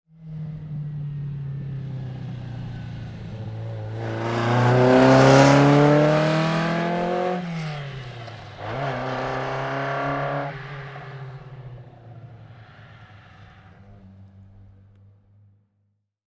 Abarth 1300 OT (1966) - Vorbeifahrt